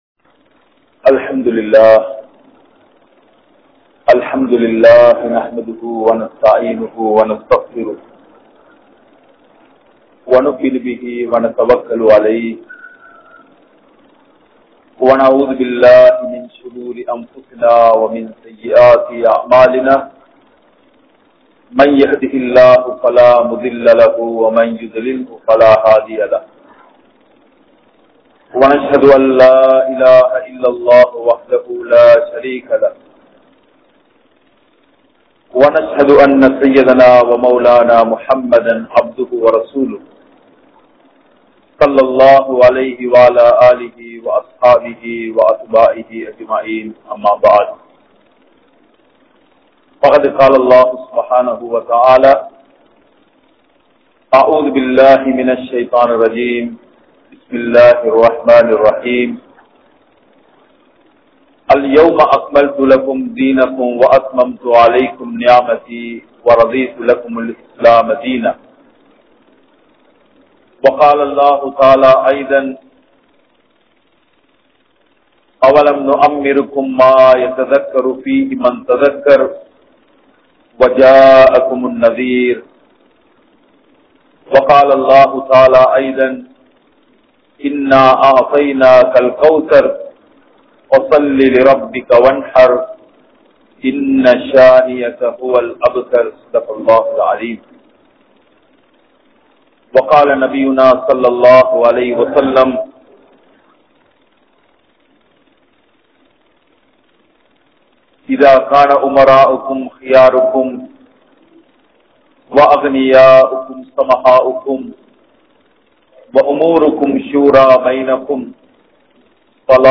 Muslimkale! Pirinthu Vidaatheerhal (முஸ்லிம்களே! பிரிந்து விடாதீர்கள்) | Audio Bayans | All Ceylon Muslim Youth Community | Addalaichenai
Wellampitiya, Polwatte, Masjidun Noor Jumua Masjidh